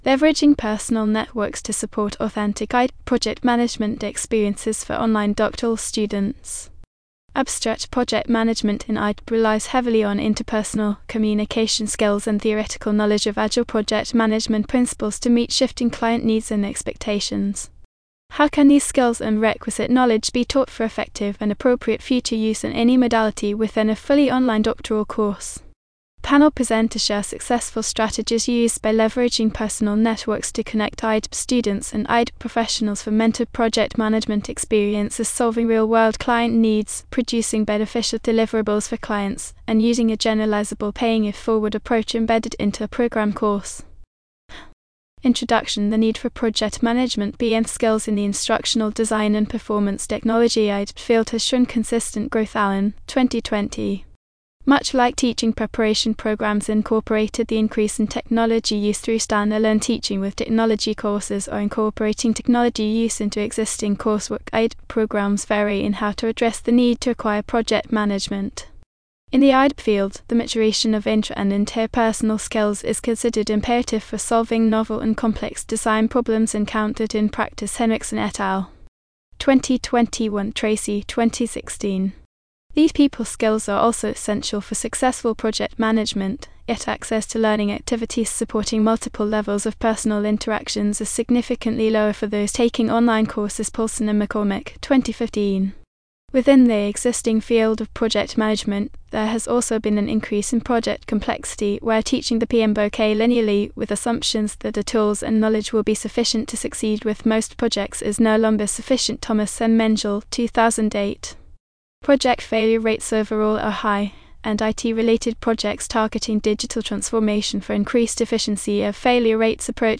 How can these skills and requisite knowledge be taught for effective and appropriate future use in any modality within a fully online doctoral course? Panel presenters share successful strategies used by leveraging personal networks to connect IDPT students and IDPT professionals for mentored project management experiences solving real-world client needs, producing beneficial deliverables for clients, and using a generalizable “paying it forward” approach embedded into a program course.